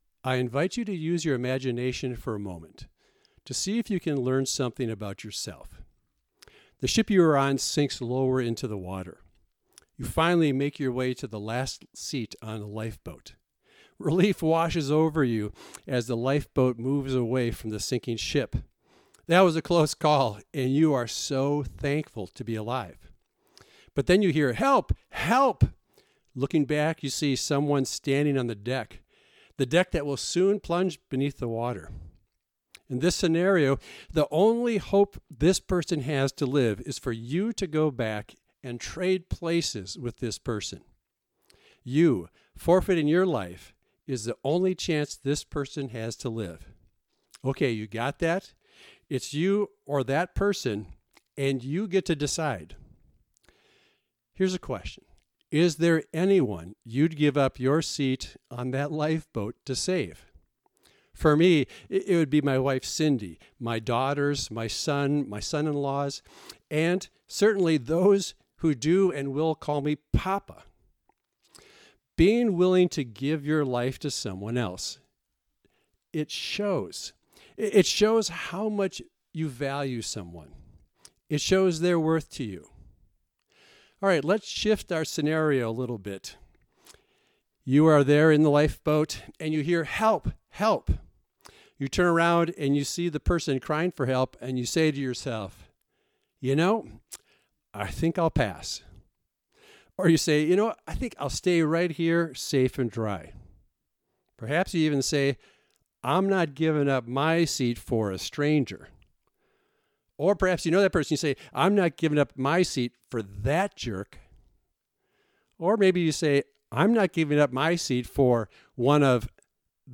#5 • Romans 5:6-8; 1 John 2:2; 1 Timothy 2:5-6; 2 Corinthians 5:14-16 Downloads & Resources • Video File • Audio File The MP3 audio file is the radio version of the message recorded for broadcast on WILLIE 105.7 AM, Siren, Wisconsin.